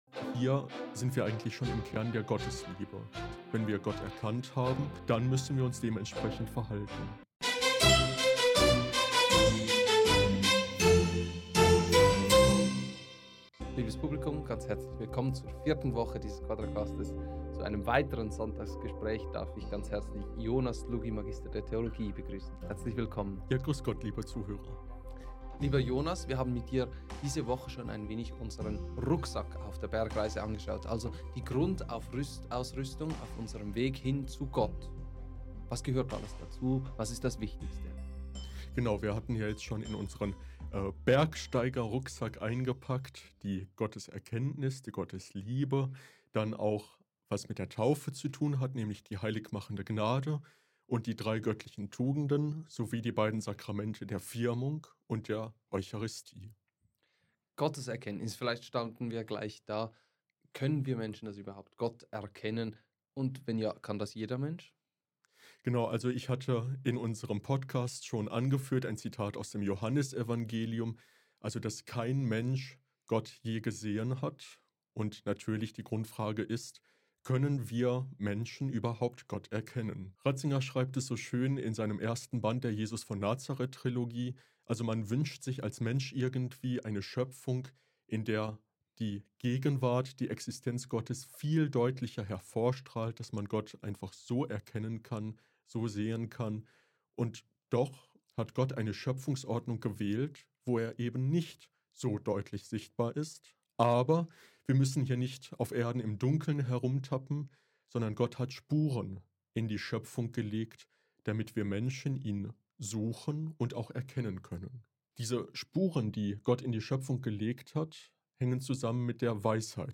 Wie das aber alles zusammengeht mit der Wissenschaft, der Logik und dem Leeren Platz auf der Bundeslade führt er für uns in diesem Sonntagsgespräch aus!